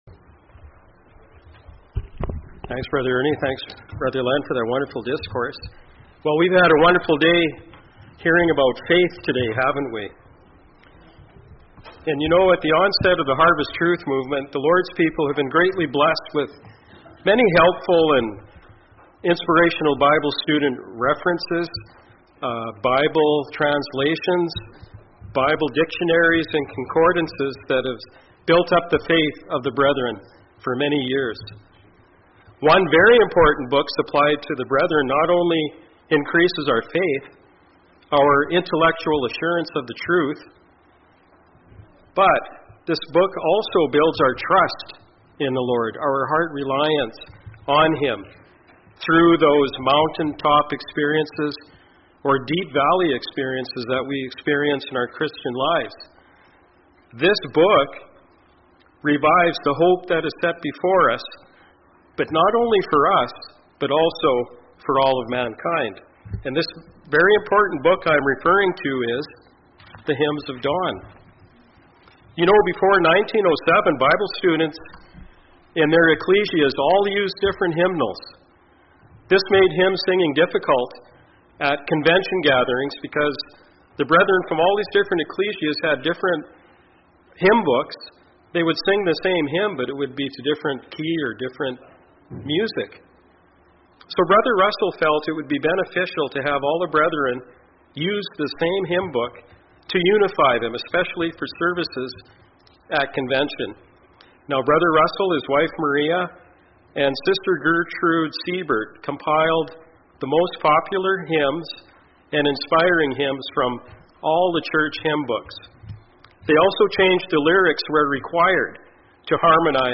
Series: 2025 Orlando Convention
Various brethren shared personal testimonies about their favorite hymns, illustrating how these songs provide comfort, inspiration, and encouragement during trials and spiritual experiences.